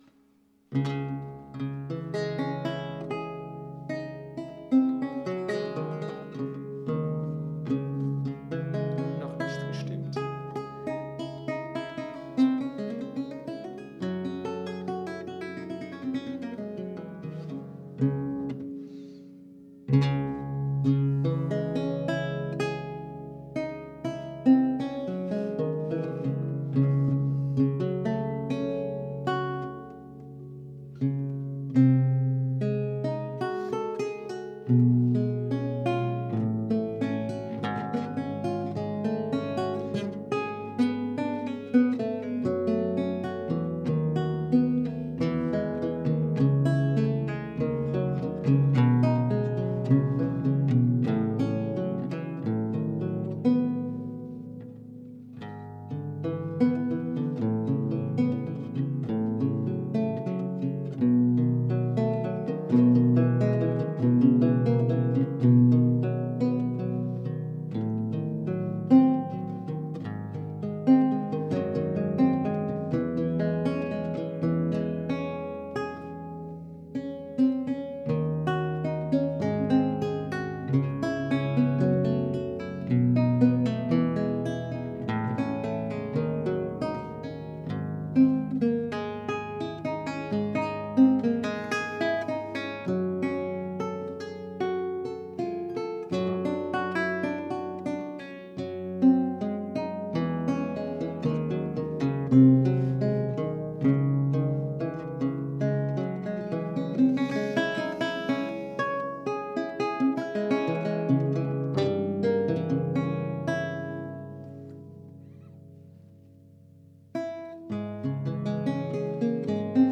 O alaúde